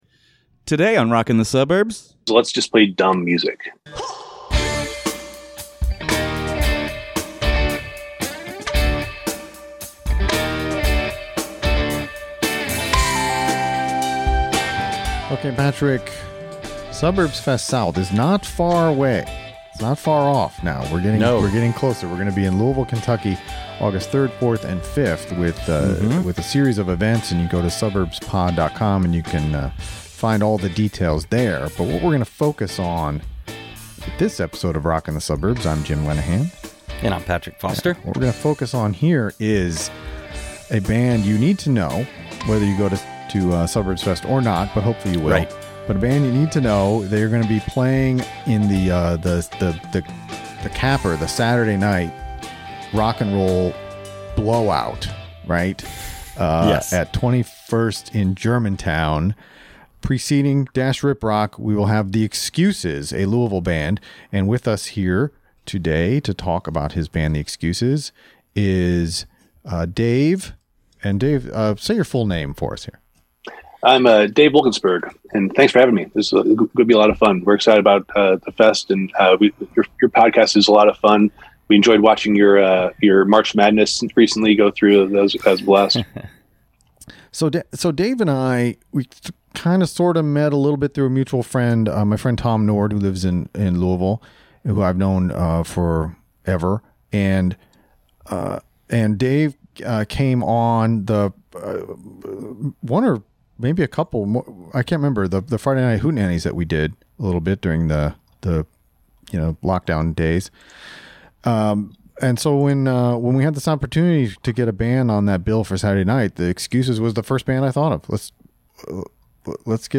Interview - The Excuses